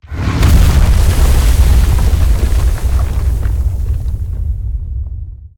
Sfx_creature_iceworm_spearattack_cut_01.ogg